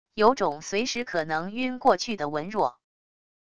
有种随时可能晕过去的文弱wav音频